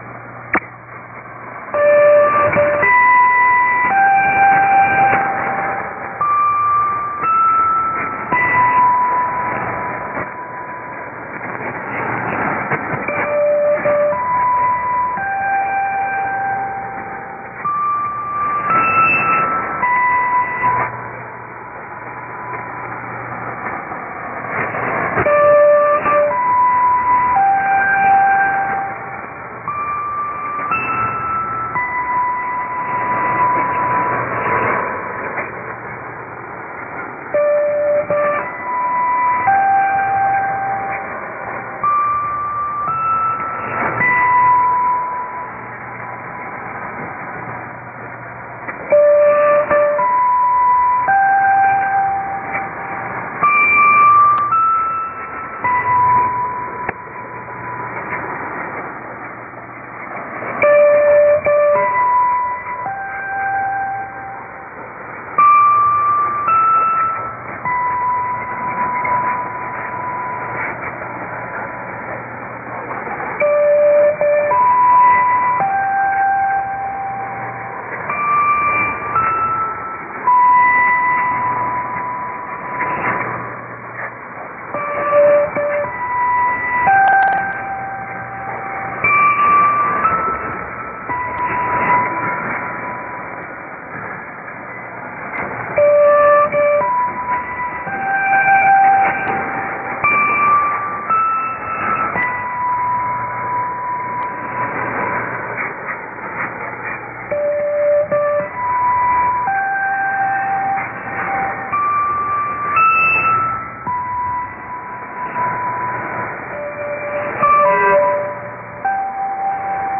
IS: interval signal
ID: identification announcement
ST: signature tune/jingle